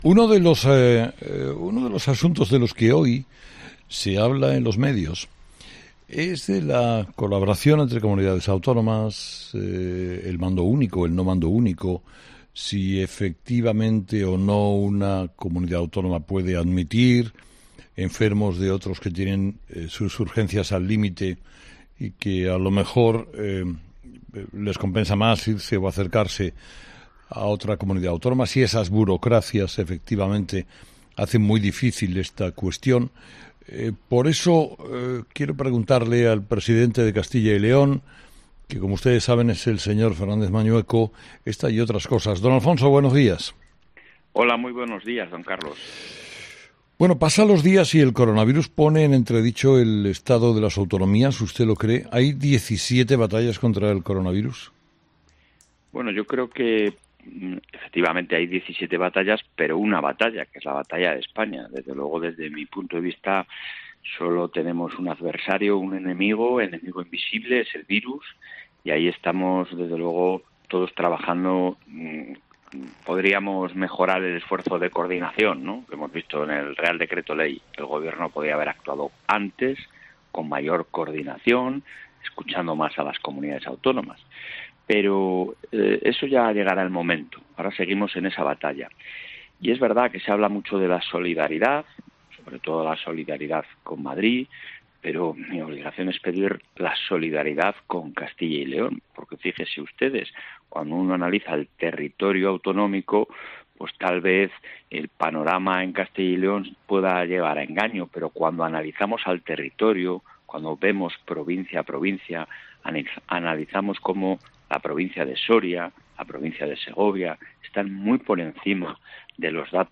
Entrevistado: "Alfonso Fernández Mañueco"